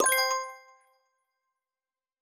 Special & Powerup (23).wav